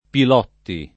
[ pil 0 tti ]